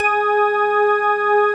B3 ROCKG#4.wav